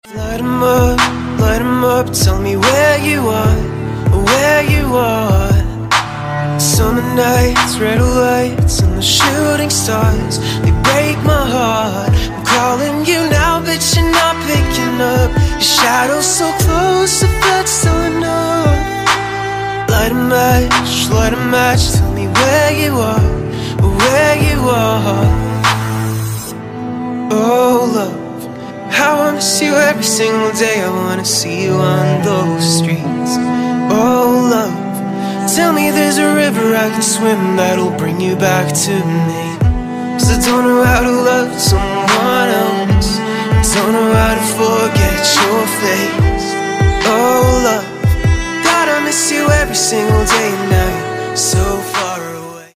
• Качество: 160, Stereo
поп
мужской вокал
dance
спокойные
скрипка
ballads
Melodic
romantic